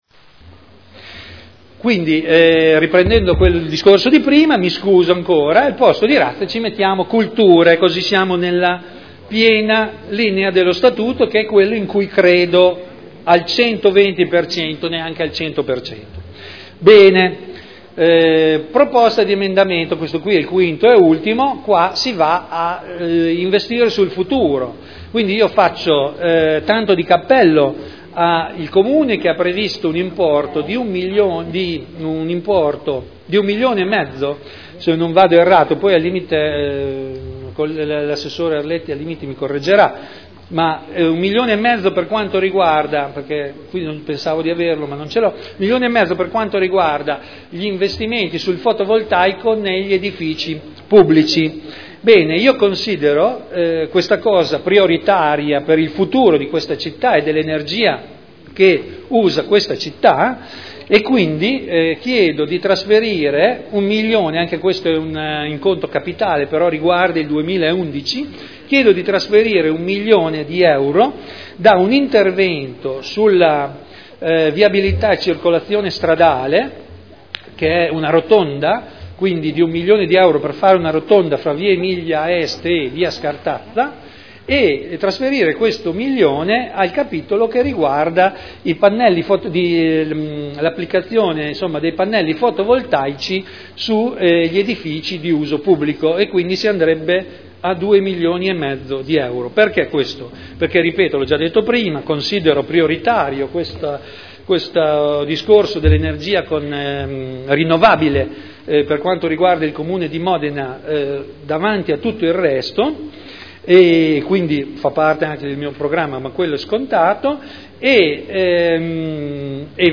Audio Consiglio Comunale
Seduta del 28 marzo 2011